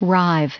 Prononciation du mot rive en anglais (fichier audio)
Prononciation du mot : rive